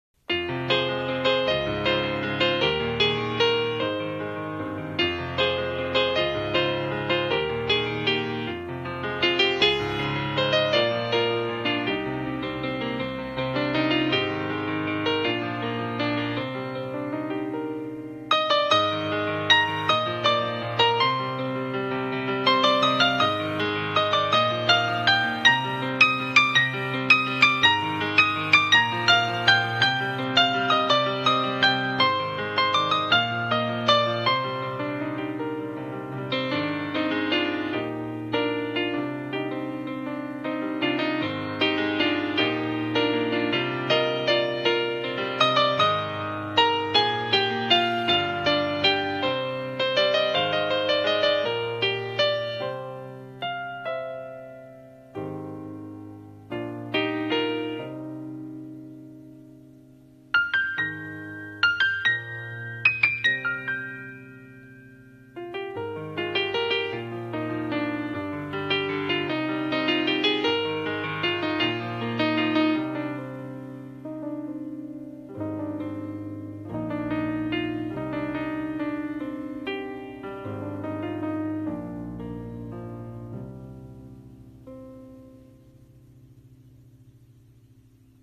二人声劇【生け贄】